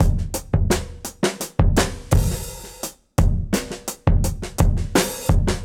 Index of /musicradar/dusty-funk-samples/Beats/85bpm
DF_BeatA_85-02.wav